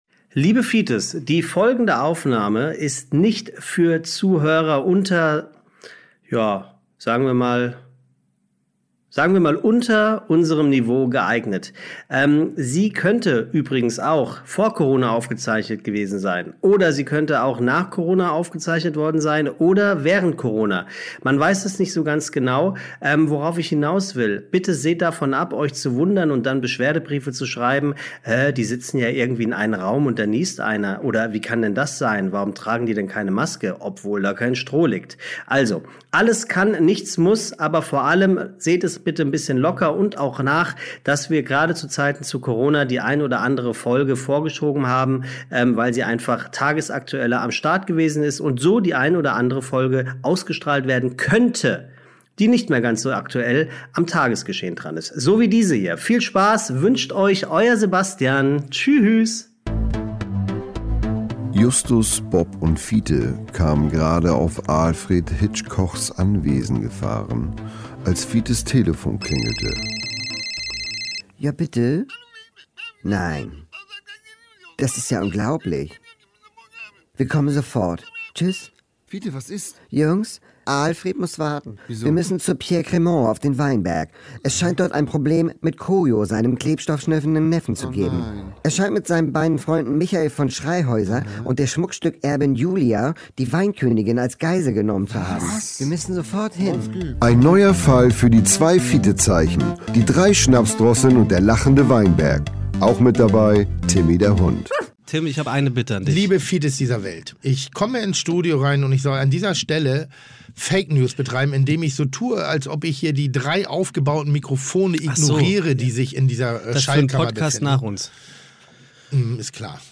In richtiger Weinstubenatmosphäre wird in großer Runde gelacht, geplaudert, Pläne geschmiedet, wieder verworfen und ab und zu auch mal tief ins Glas geschaut.